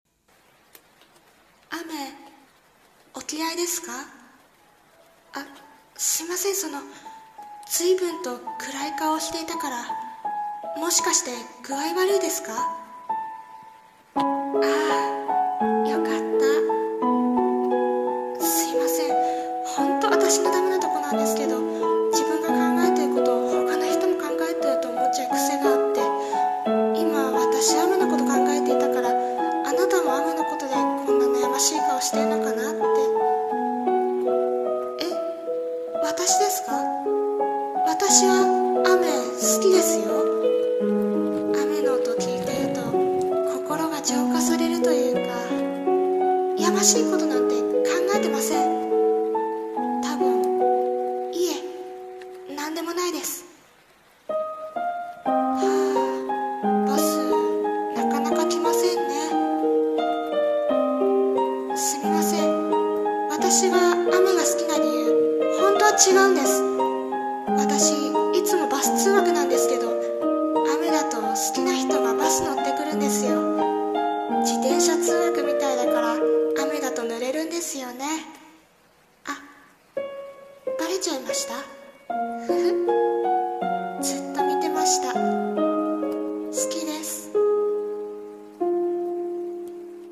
さんの投稿した曲一覧 を表示 【声劇台本】雨、バス待ち【一人声劇】